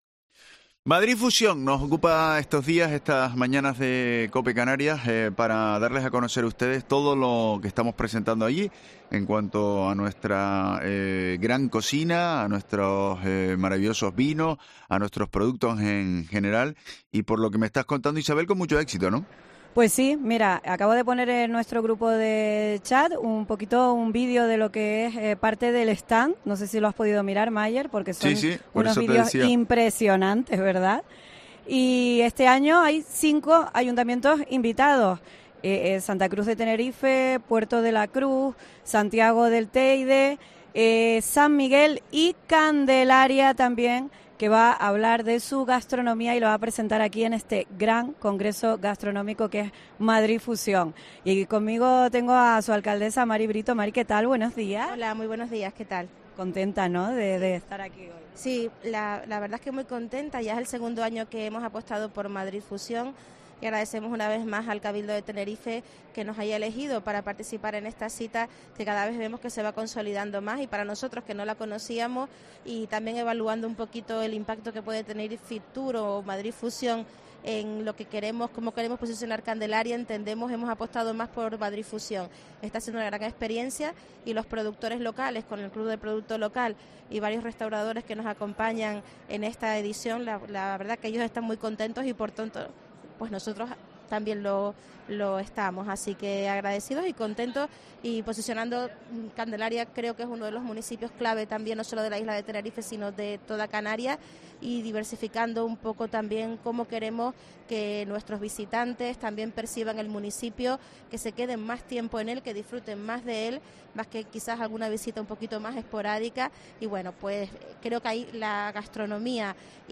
Entrevista Mari Brito, alcaldesa de Candelaria en Madrid Fusión